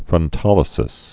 (frŭn-tŏlĭ-sĭs)